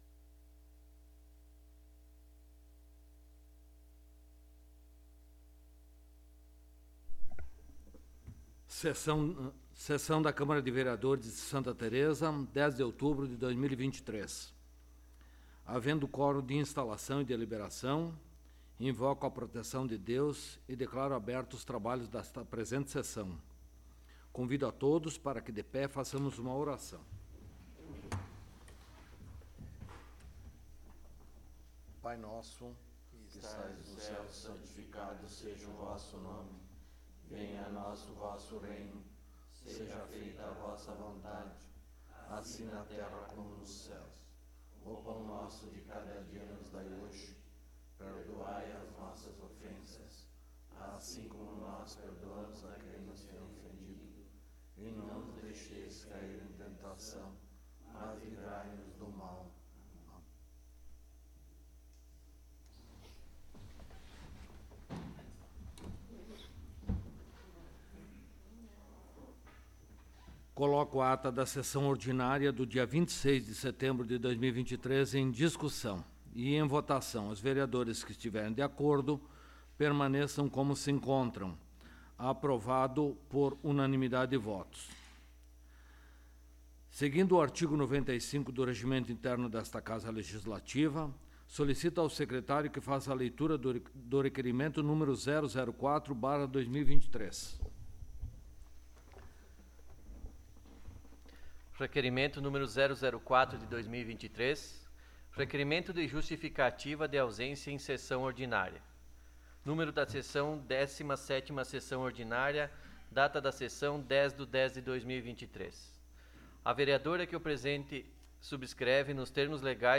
17ª Sessão Ordinária de 2023